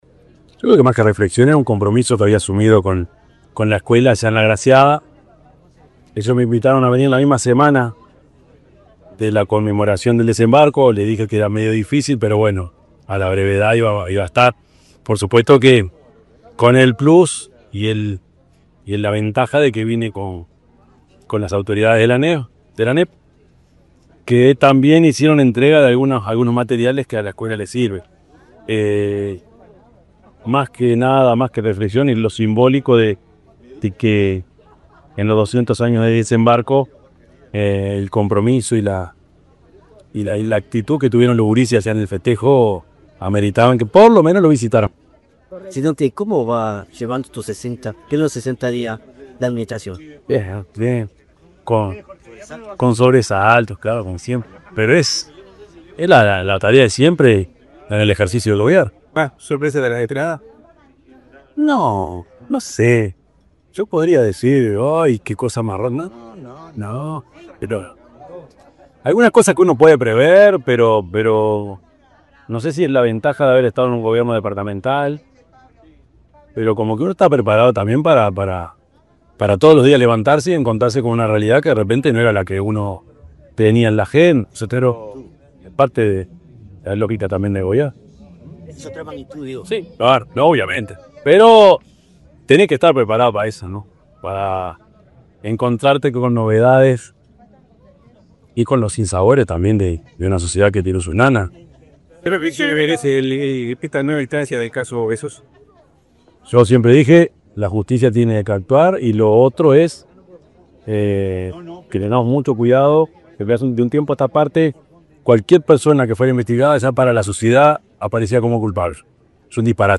Declaraciones del presidente Yamandú Orsi
El presidente de la República, profesor Yamandú Orsi, dialogó con la prensa, luego de visitar la escuela n.° 10 en la ciudad de Mercedes, departamento